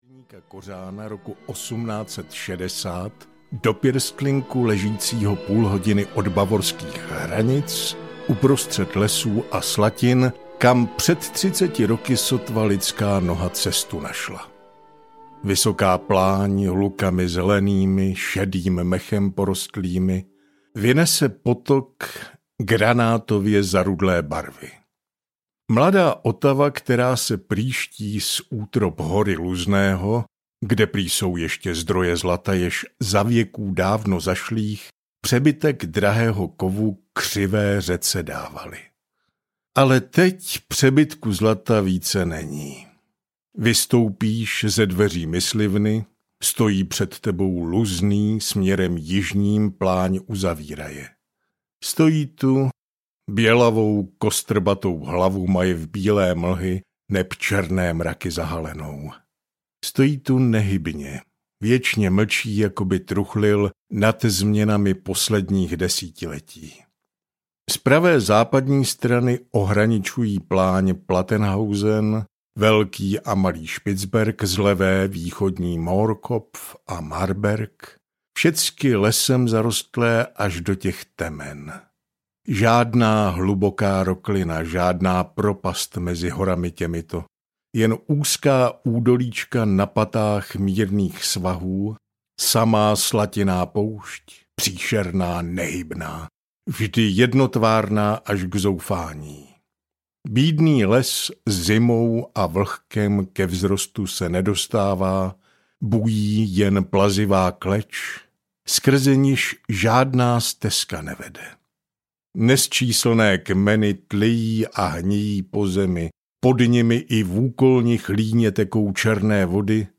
Ze světa lesních samot audiokniha
Ukázka z knihy